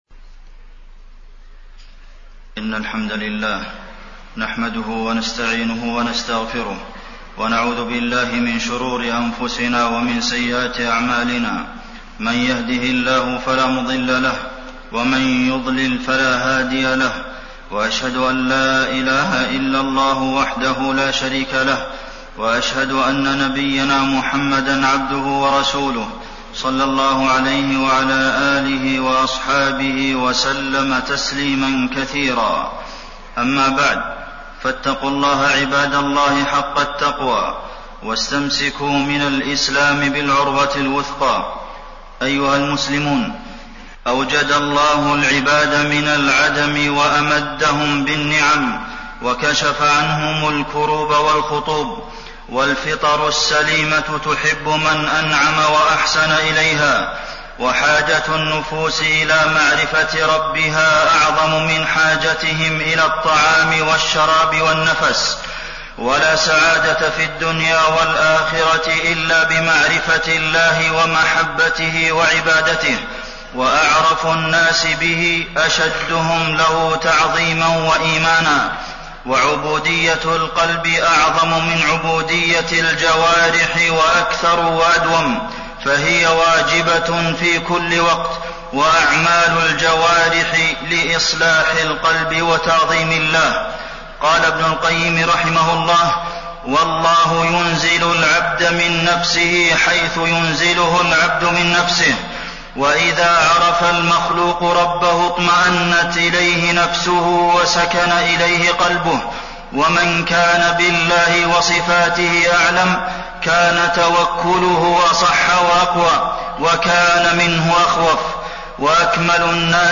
تاريخ النشر ١٨ جمادى الأولى ١٤٣٢ هـ المكان: المسجد النبوي الشيخ: فضيلة الشيخ د. عبدالمحسن بن محمد القاسم فضيلة الشيخ د. عبدالمحسن بن محمد القاسم دلائل قدرة الله تعالى وعظمته